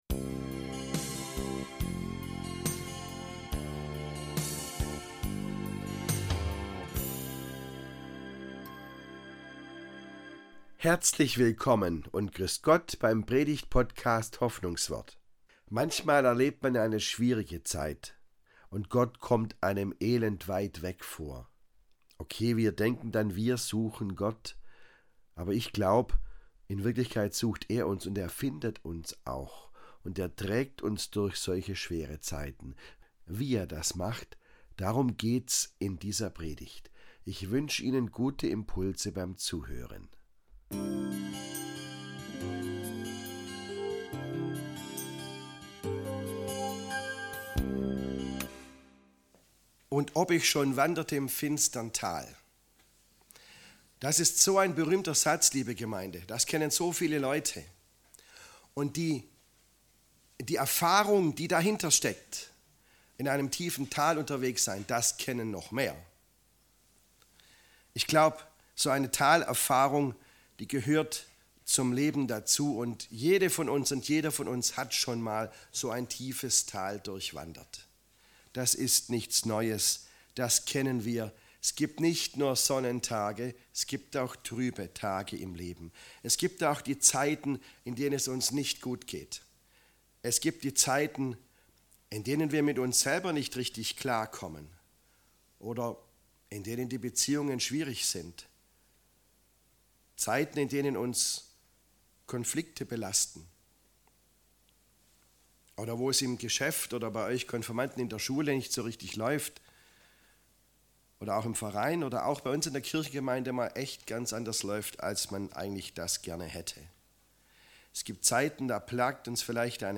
Wie Gott uns durch schwierige Zeiten trägt ~ Hoffnungswort - Predigten